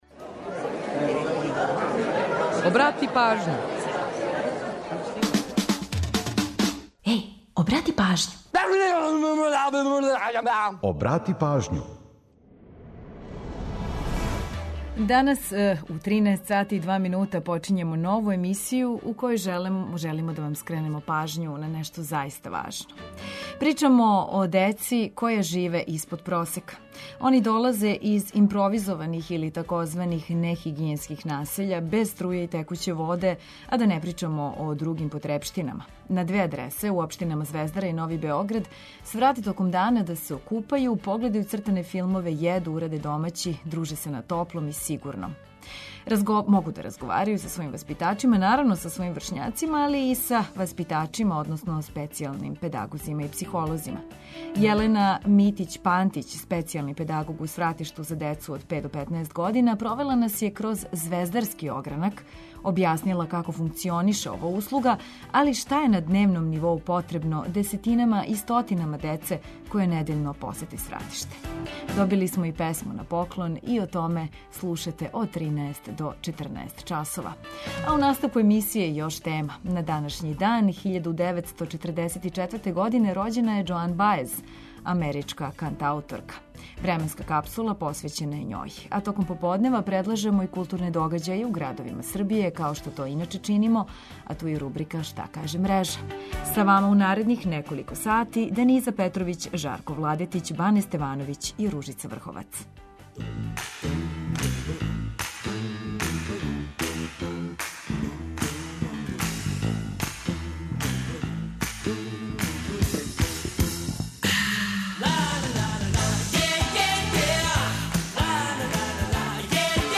Добили смо и песму на поклон, а све ово слушате од 13 до 14 часова.